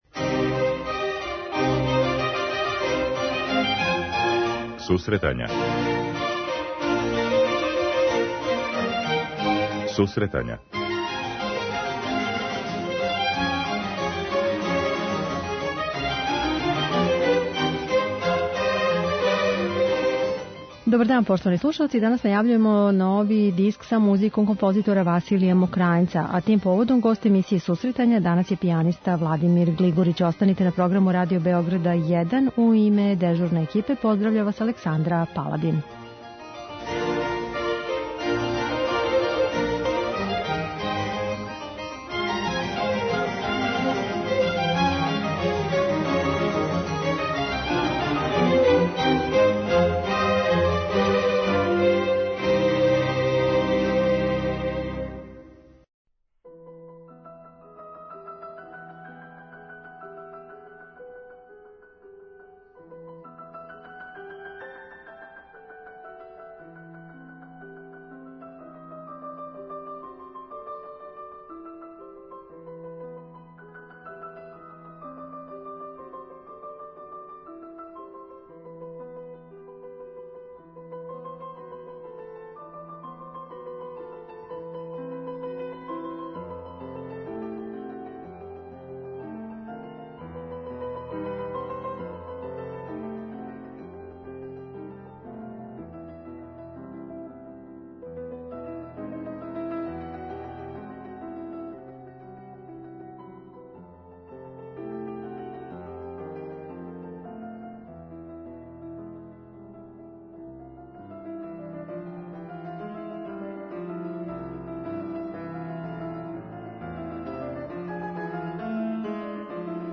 Са овим уметником разговараћемо о изазовима свирања Мокрањчеве музике и искуствима са снимања за инострану издавачку кућу, а такође слушаоци ће бити у прилици да чују одабране композиције са овог диска.